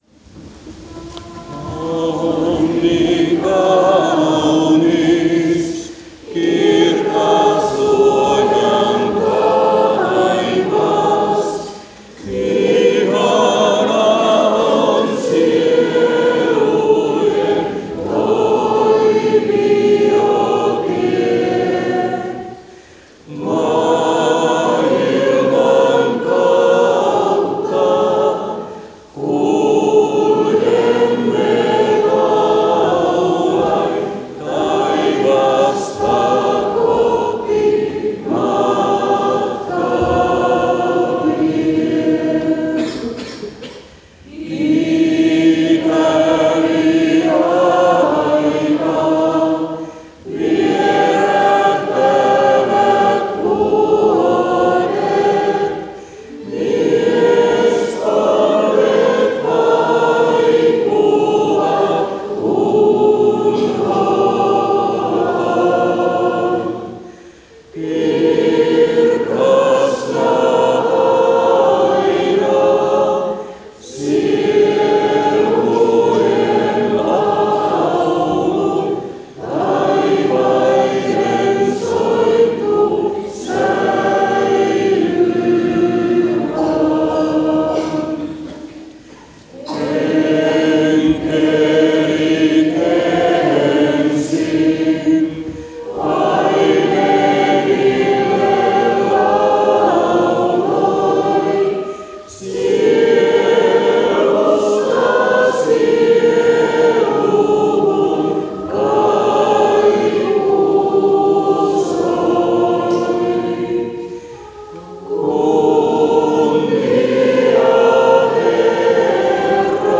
Lyhtyekulkue saapuu Tyrvään Pyhälle Olaville.
Veisataan virrestä 25 kolme säkeistöä.